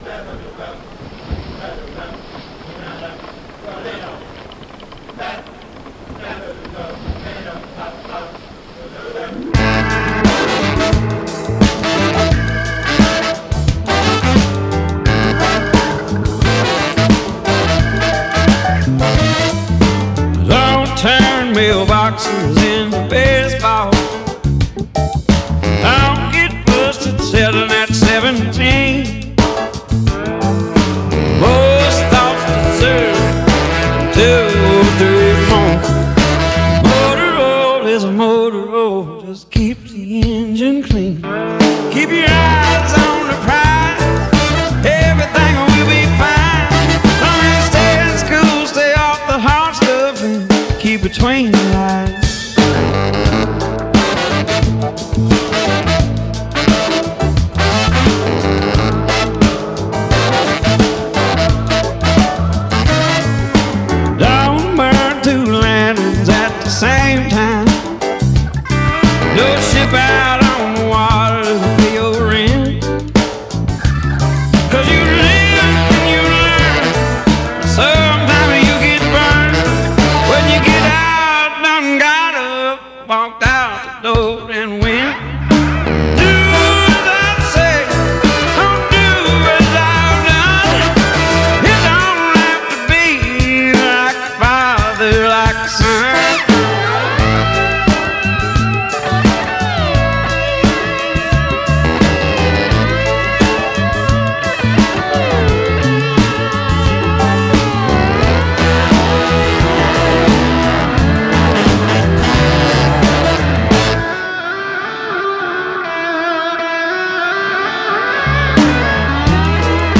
vintage country sounds